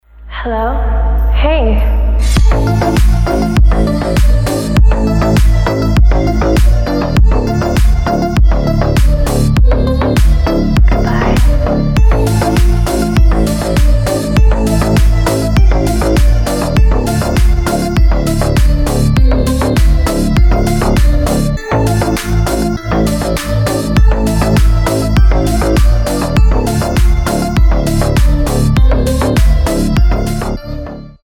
• Качество: 320, Stereo
атмосферные
басы
кайфовые
G-House